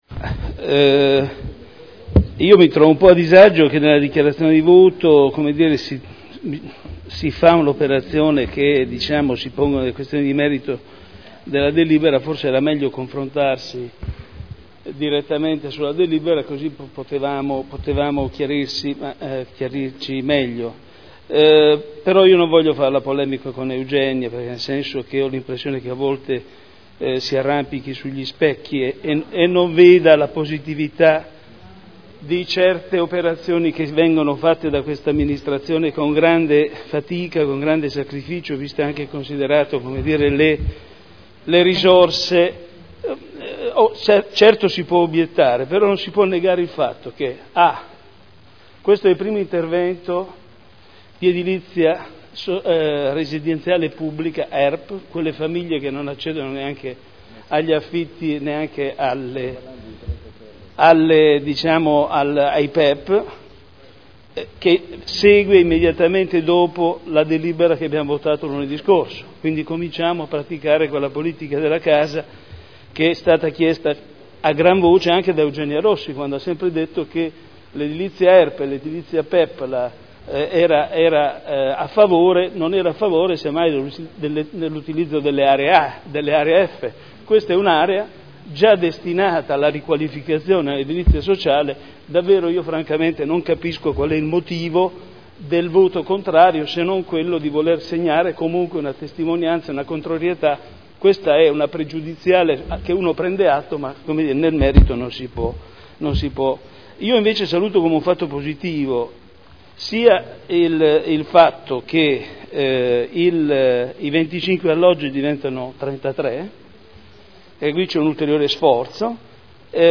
Michele Andreana — Sito Audio Consiglio Comunale